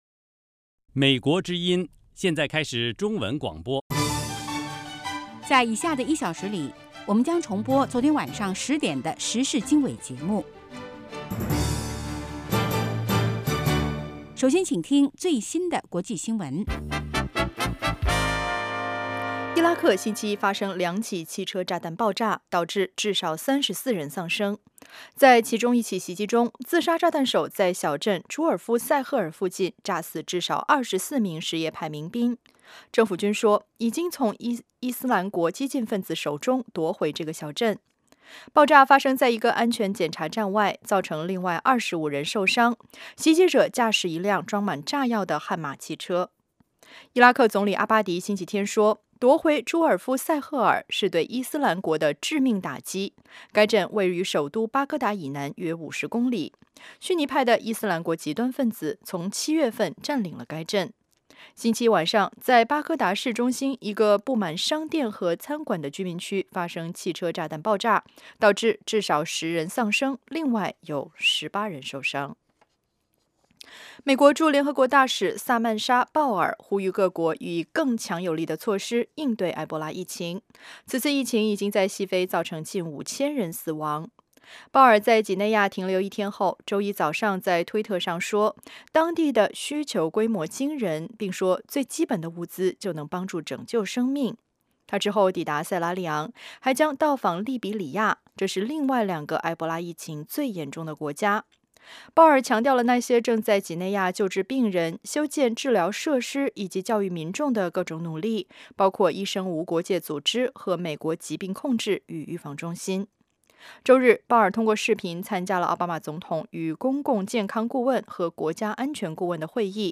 早6-7点广播节目